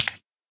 Minecraft Version Minecraft Version latest Latest Release | Latest Snapshot latest / assets / minecraft / sounds / mob / guardian / flop3.ogg Compare With Compare With Latest Release | Latest Snapshot
flop3.ogg